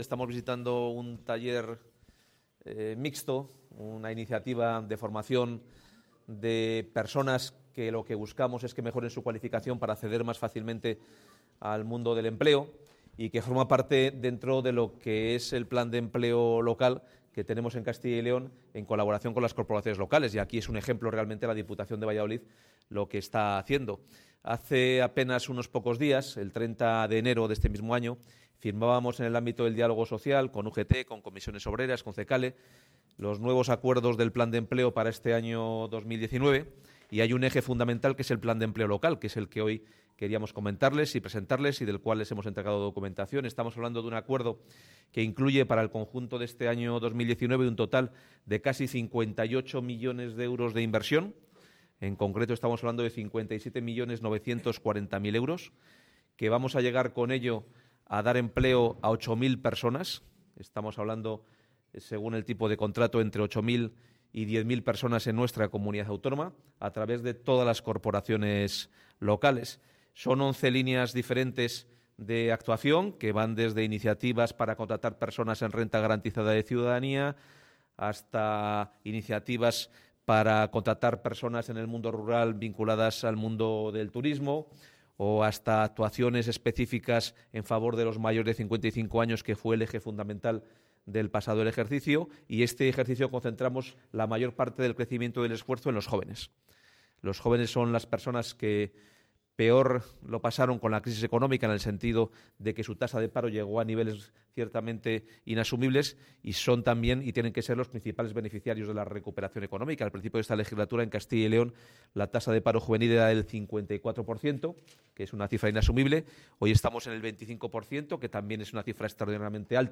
Declaraciones del consejero de Empleo.